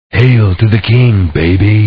hail.ogg